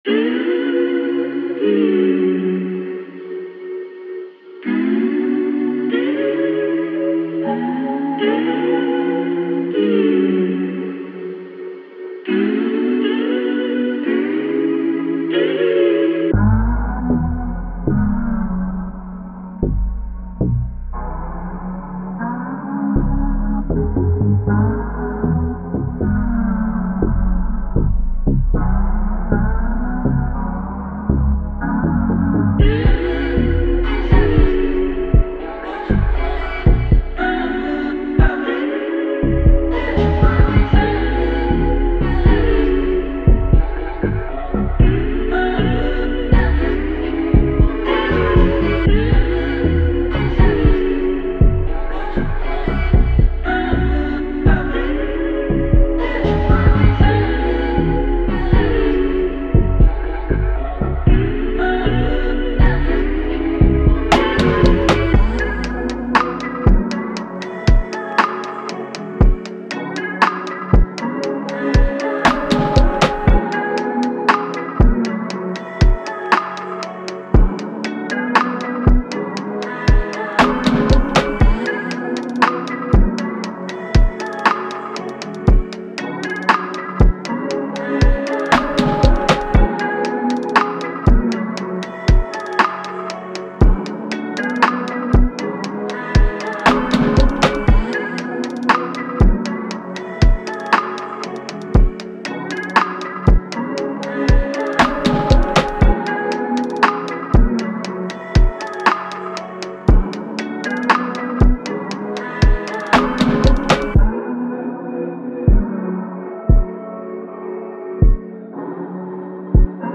R&B
F#min